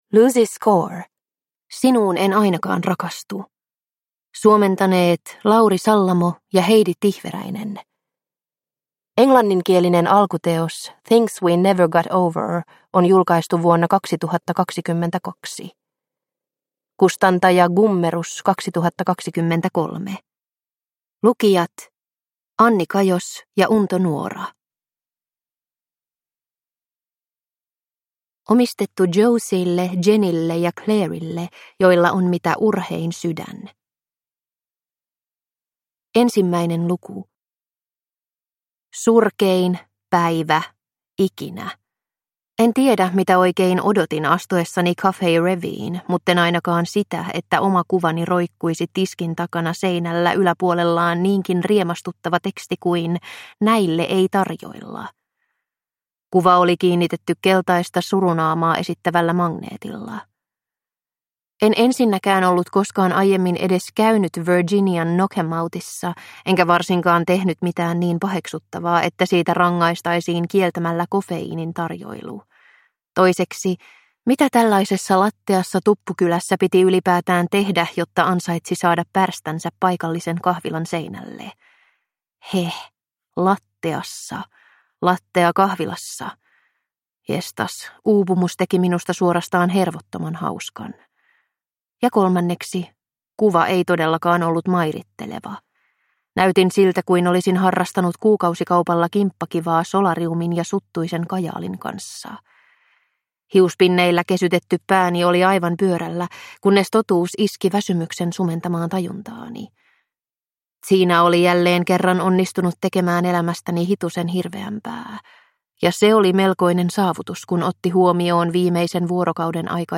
Sinuun en ainakaan rakastu – Ljudbok – Laddas ner